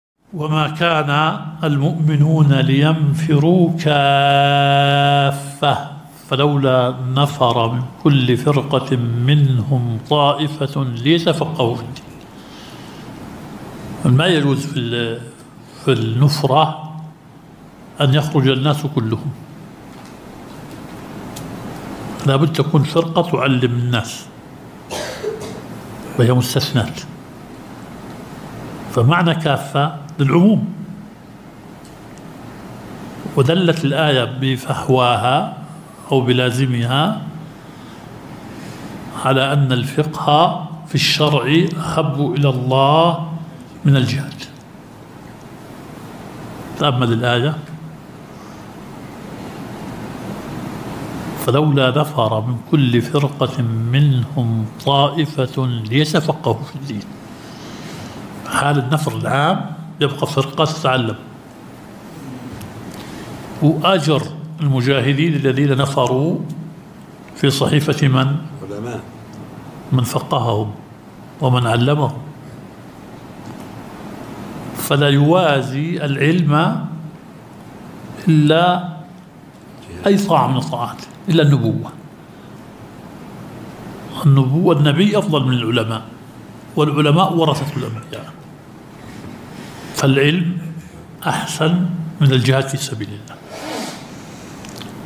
الدرس الخامس – شرح مبحث العام والخاص في أصول الفقه.